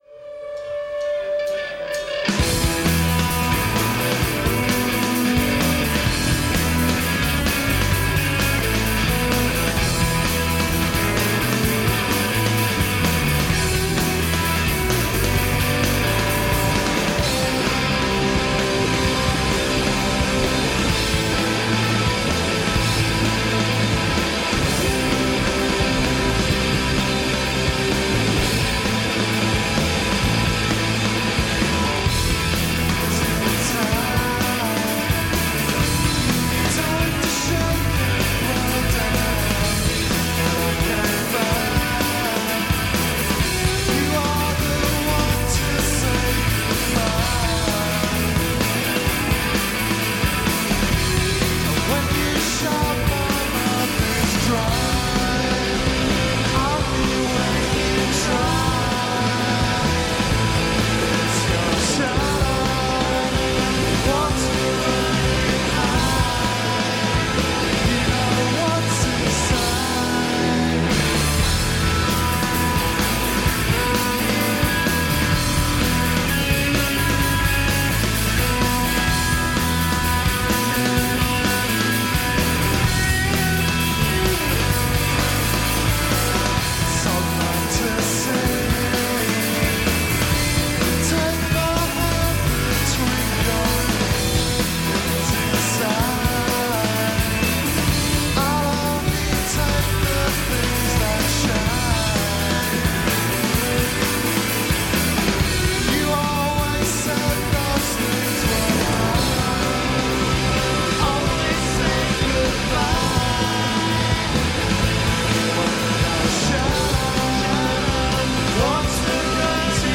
An early gig, live at The Marquee Club in 1991.
Caught smack in the middle between Shoegaze and Grunge